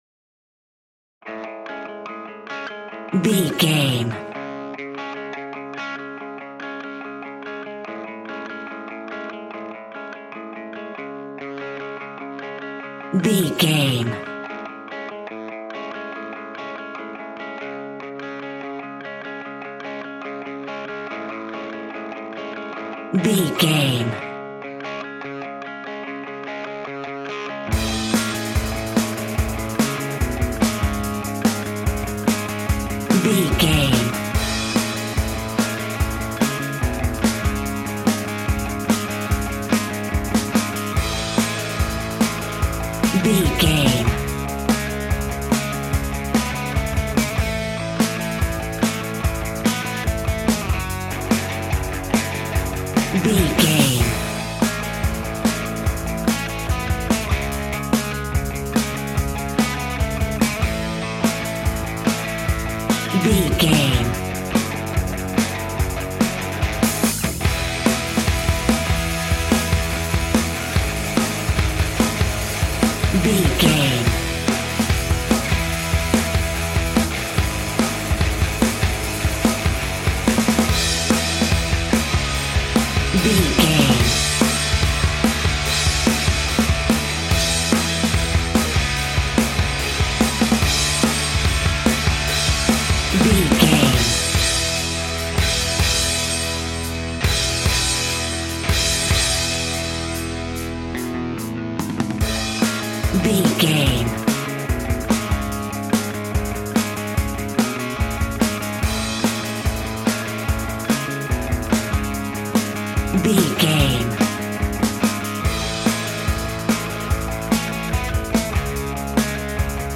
Epic / Action
Fast paced
Ionian/Major
hard rock
heavy metal
dirty rock
rock instrumentals
Heavy Metal Guitars
Metal Drums
Heavy Bass Guitars